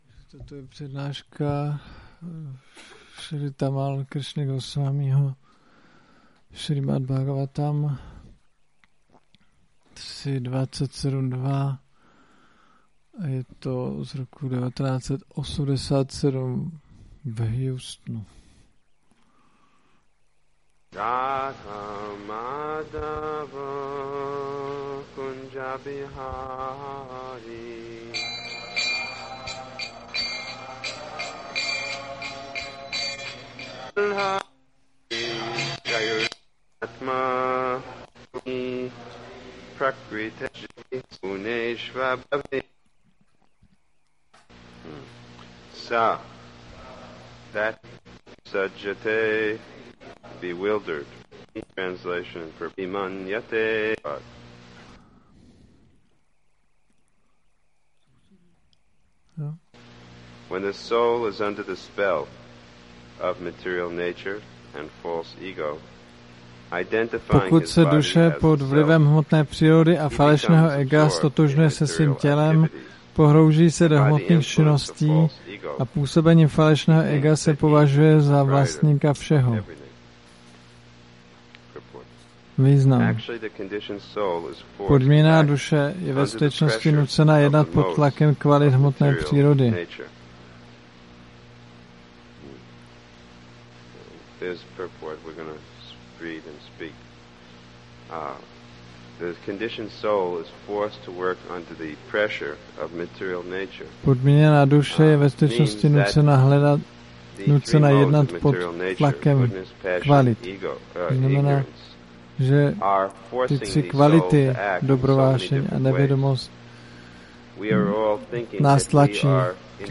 2020-09-04-ACPO Tamal Krishna Gosvámí – Přednáška